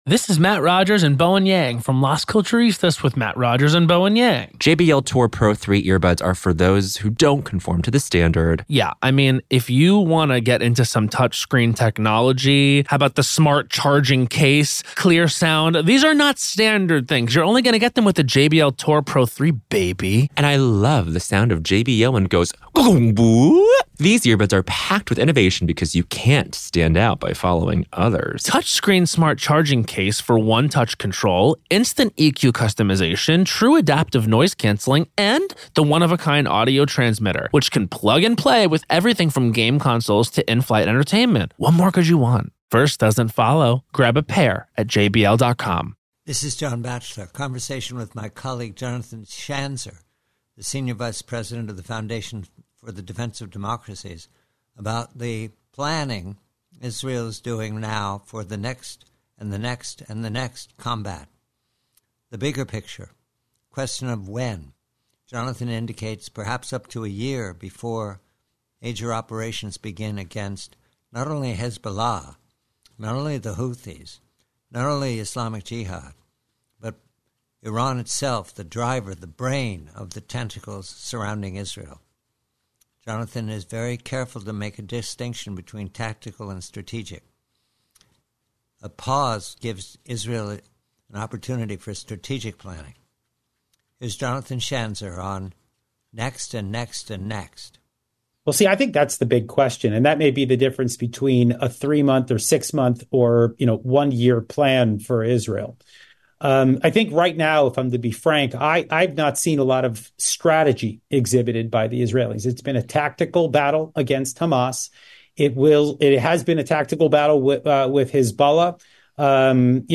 PREVIEW: IDF: AFTER GAZA: Conversation